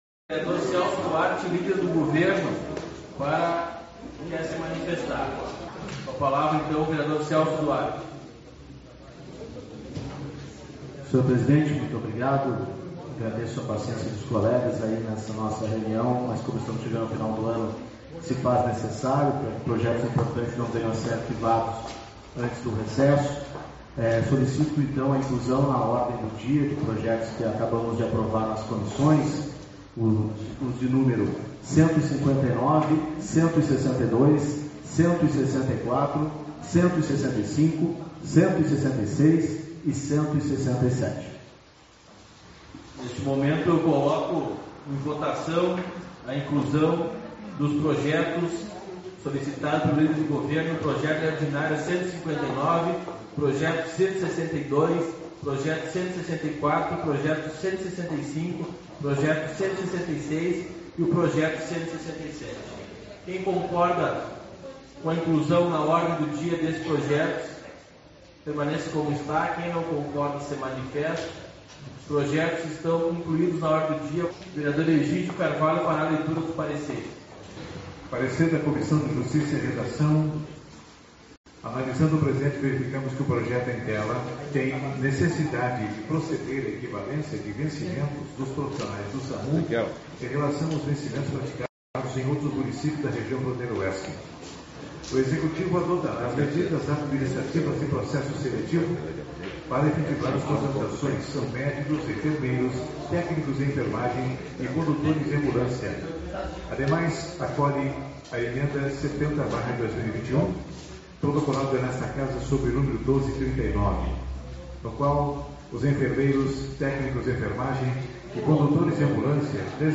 16/12 - Reunião Ordinária